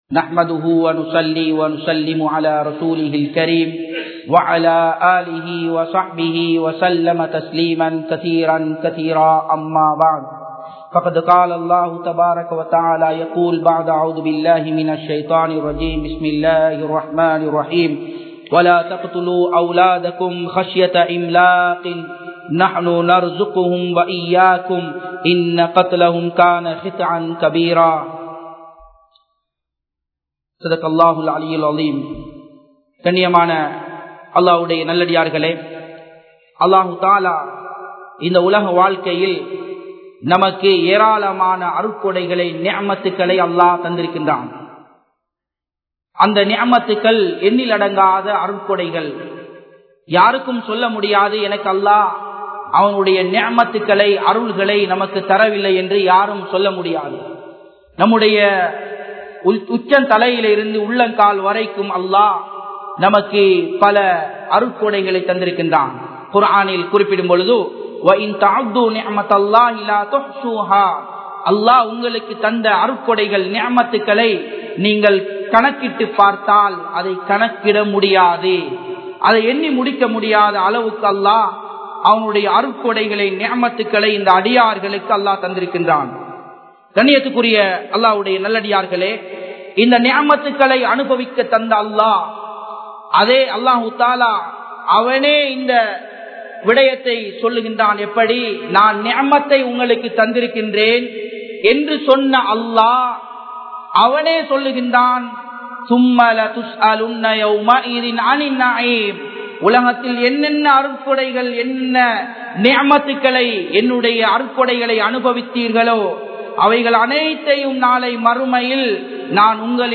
Islamiya Parvaiel Pen Kulanthai (இஸ்லாமிய பார்வையில் பெண் குழந்தை) | Audio Bayans | All Ceylon Muslim Youth Community | Addalaichenai
Japan, Nagoya Port Jumua Masjidh